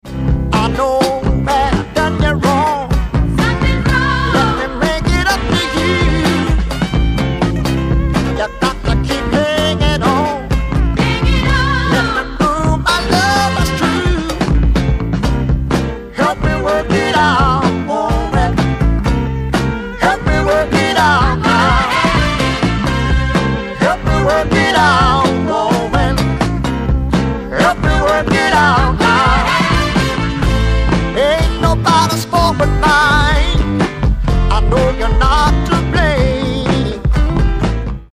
ギター、ヴォーカル
オルガン、ヴォーカル、フロント・マン
ドラムス